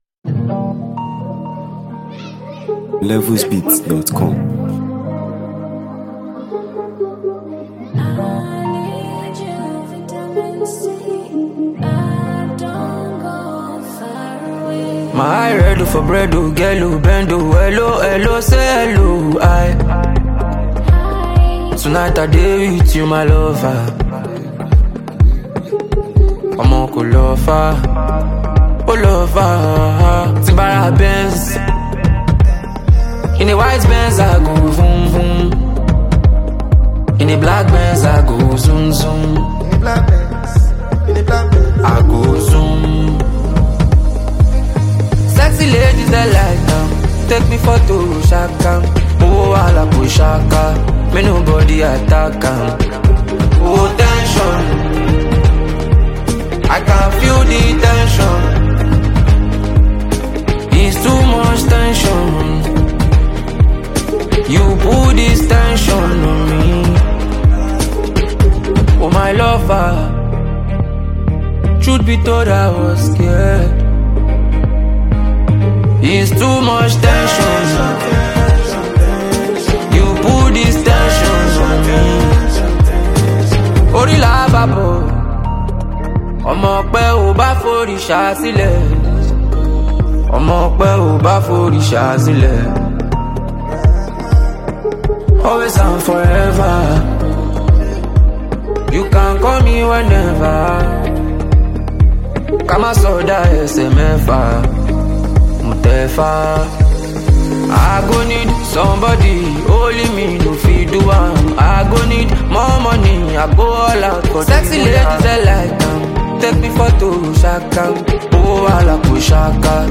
contemporary Afrobeats